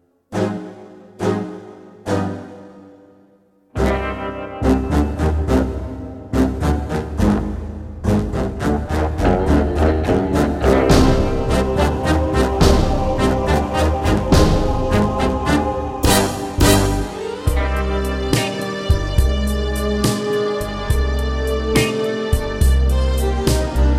Three Semitones Up Jazz / Swing 4:14 Buy £1.50